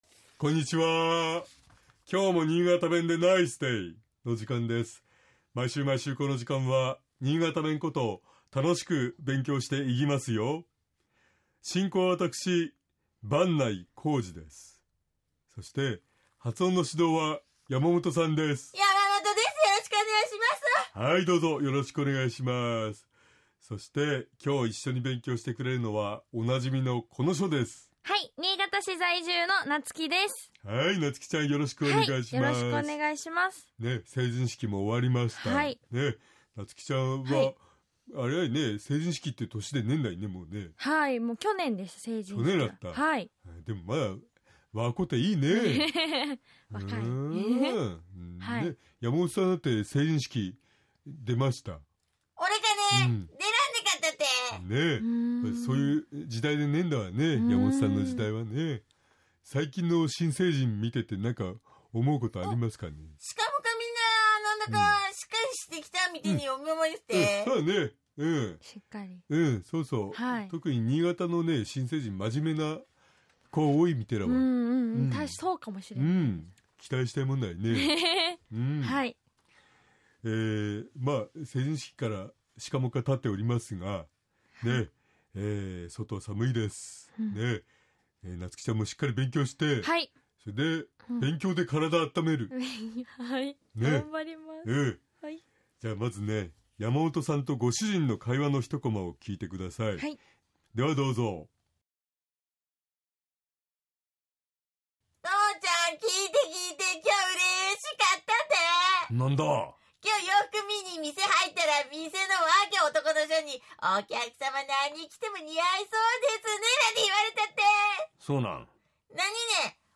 BSN新潟放送｜ラジオ｜今すぐ使える新潟弁 by Podcast｜1月20日（月）今日も新潟弁でナイスデイ！